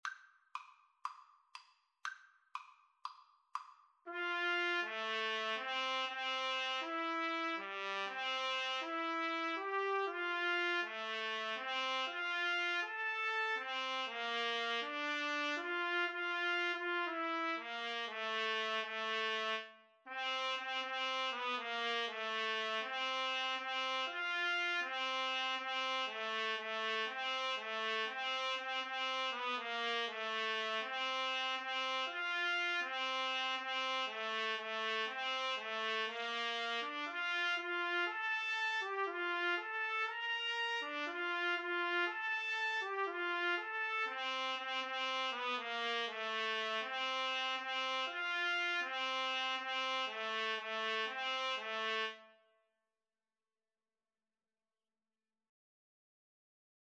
~ = 120 Fast, calypso style